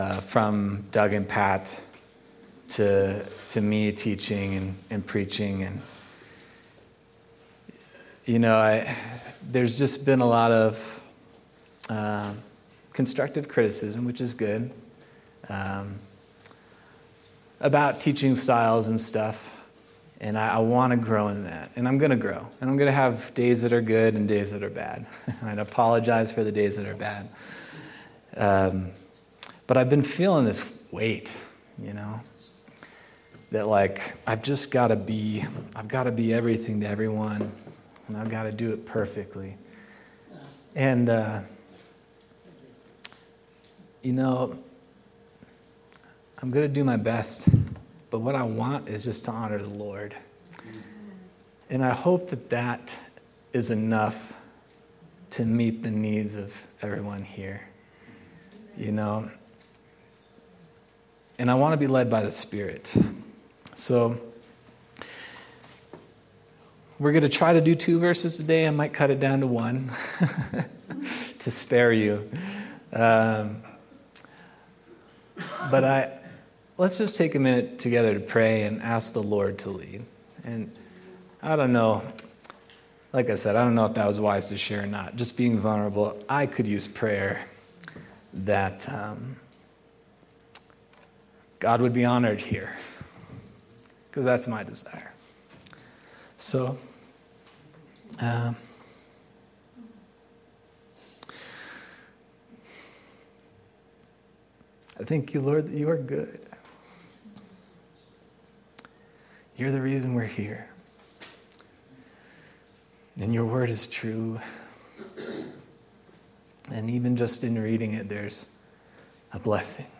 February 16th, 2025 Sermon